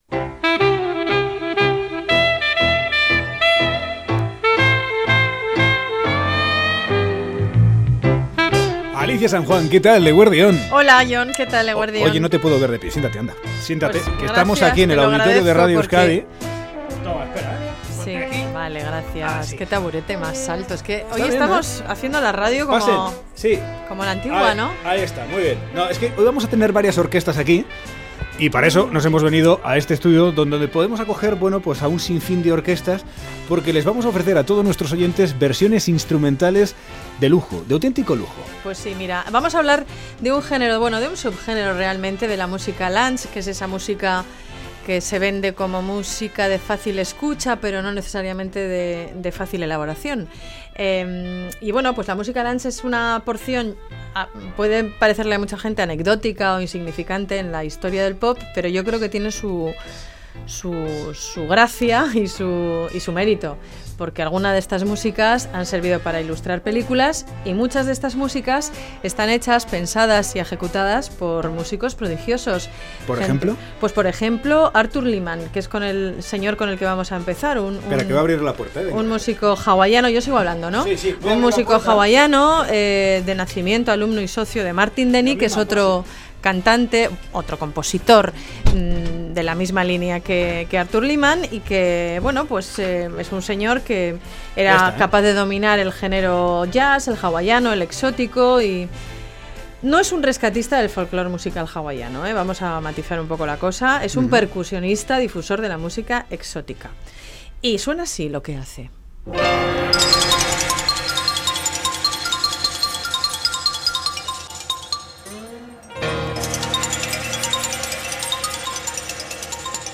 Música que suena a Asia, Africa, el Pacífico Sur y Latinoamérica… ¡sin serlo!